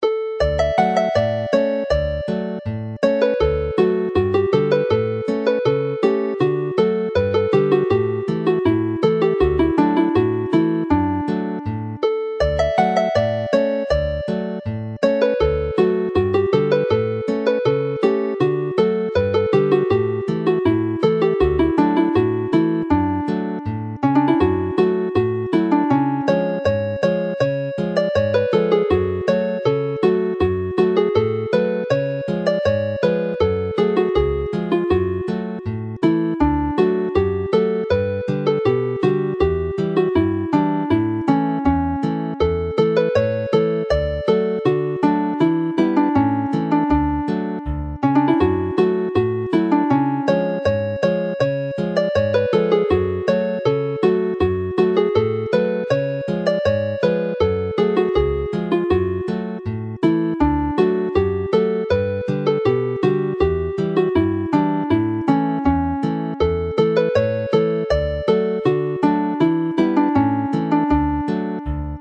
Alawon Cymreig - Set Sawdl y Fuwch - Welsh folk tunes to play -
Play the tune slowly